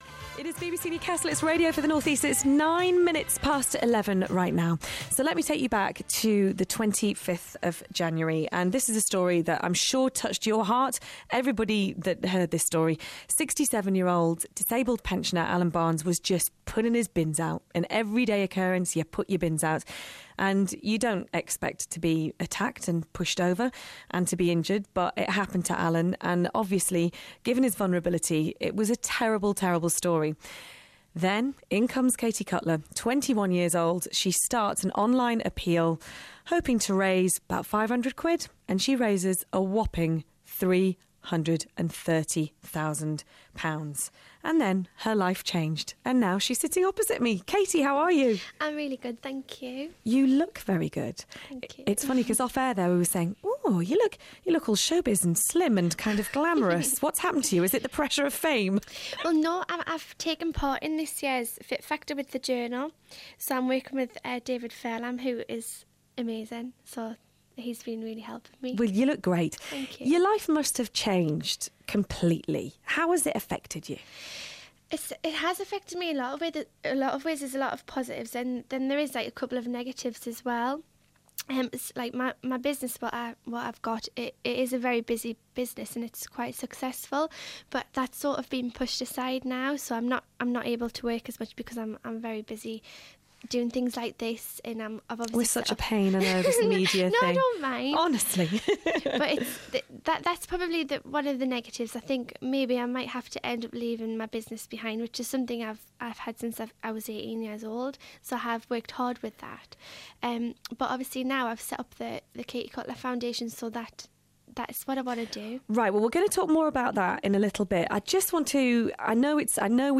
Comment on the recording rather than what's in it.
in the studio